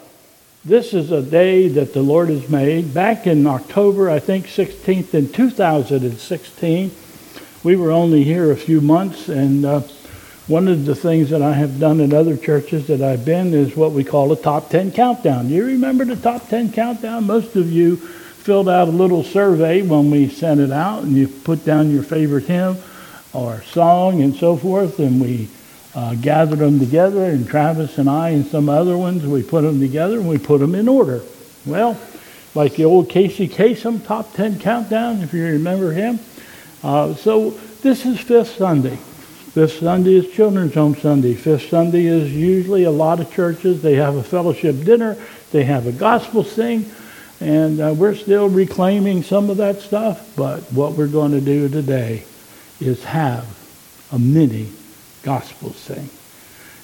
Mini Gospel Sing